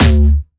Jumpstyle Kick 3
10 D2.wav